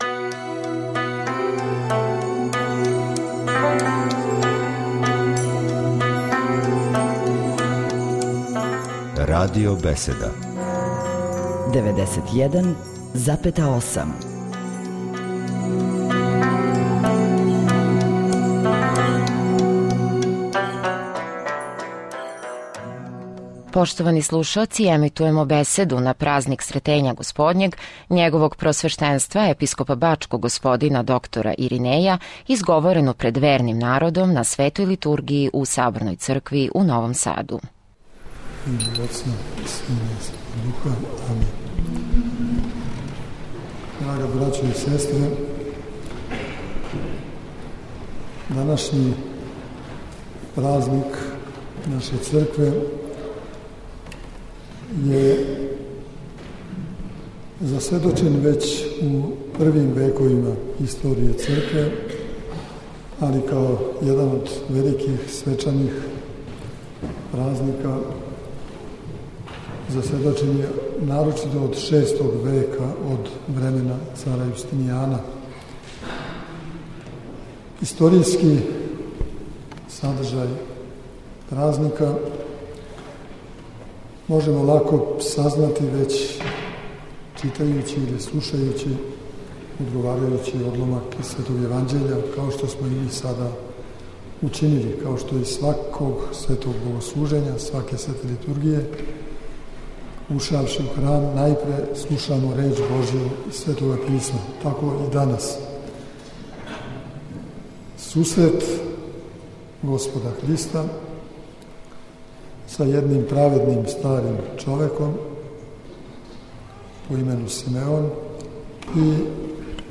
На празник Сретења Господњег, Његово Преосвештенство Епископ бачки Господин Иринеј служио је свету Литургију у Светогеоргијевском Саборном храму у Новом Саду, уз саслужење братства овог храма и новосадских ђаконâ.